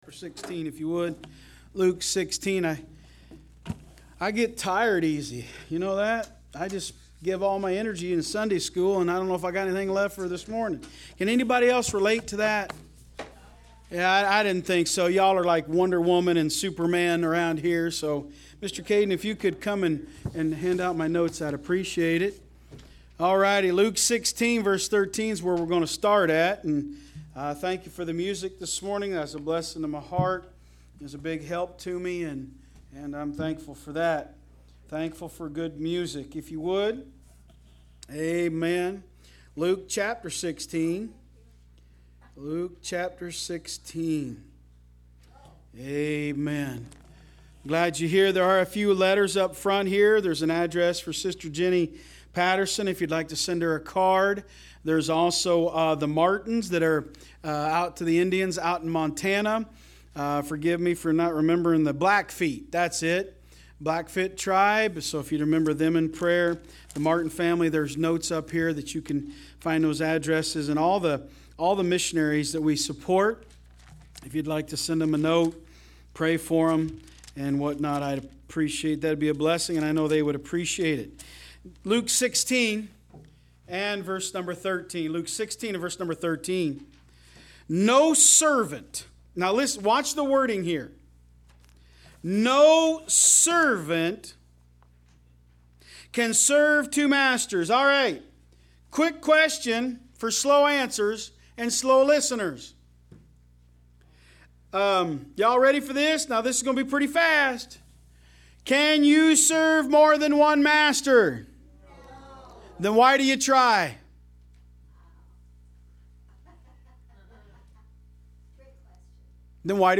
Online Sermons – Walker Baptist Church
From Series: "AM Service"